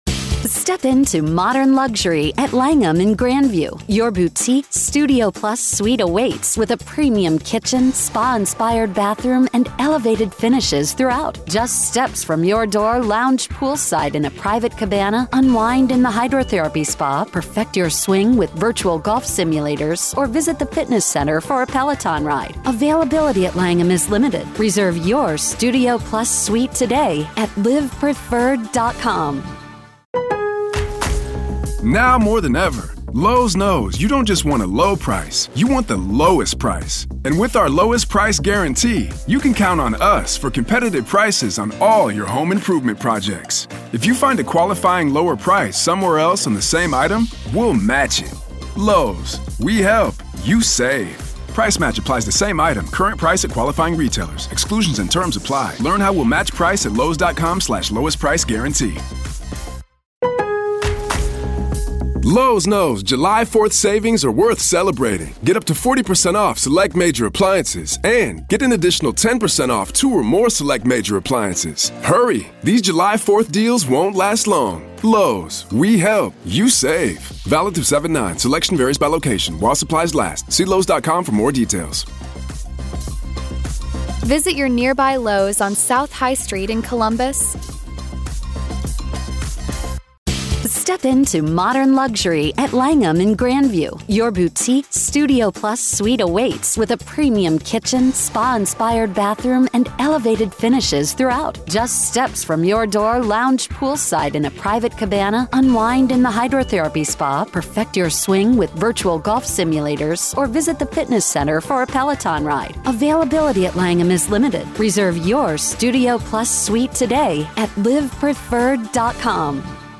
Dr. Katherine Ramsland Interview Behind The Mind Of BTK Part 4